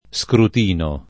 Skrut&no], scruttinio [Skrutt&nLo], ecc.